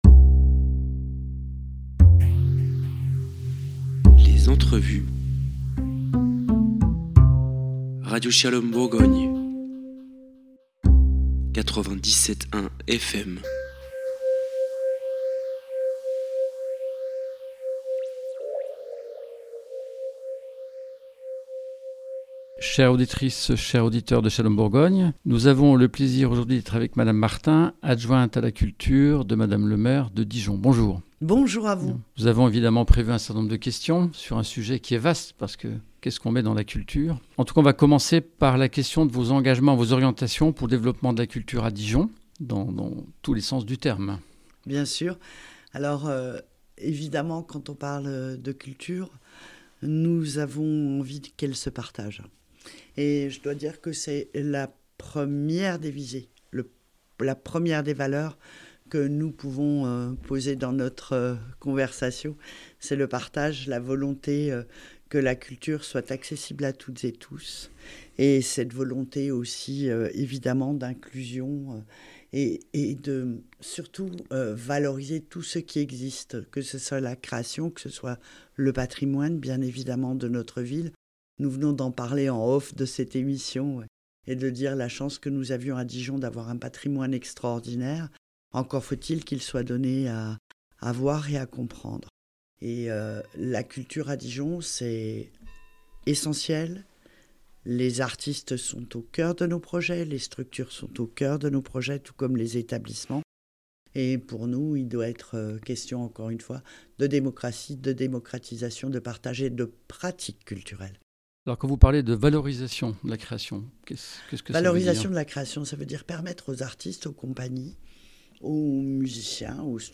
16 septembre 2025 Écouter le podcast Télécharger le podcast La Culture, la passion pour une politique publique essentielle à la cohésion sociale Le 5 septembre 2025, Madame Christine Martin, deuxième adjointe à Madame le Maire de Dijon, déléguée à la Culture, a reçu « Shalom Bourgogne » au cœur du Palais des Ducs et des Etats de Bourgogne. Quel budget pour la Culture ?
Un entretien passionné qui donne aux auditrices et aux auditeurs une approche concrète de la vie culturelle de Dijon et des valeurs que porte la Collectivité qui considère l’éducation artistique et culturelle comme un outil de cohésion sociale et la diversité culturelle comme une force pour la Cité.